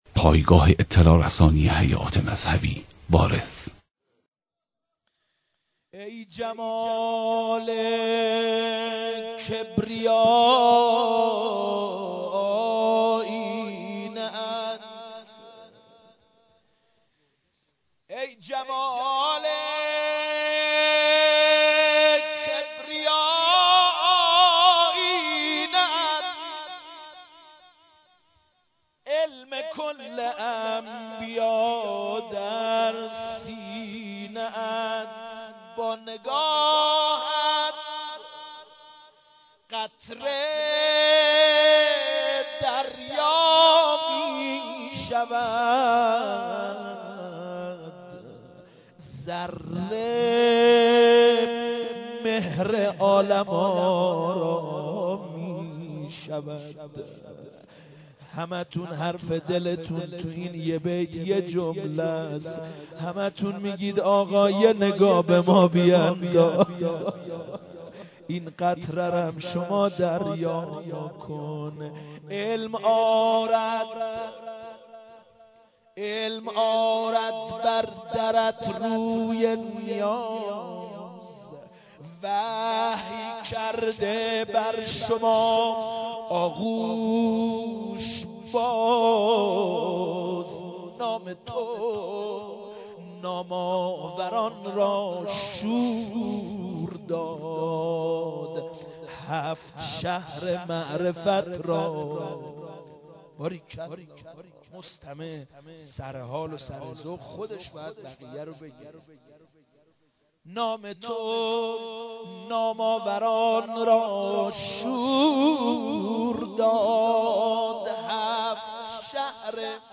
مداحی
روضه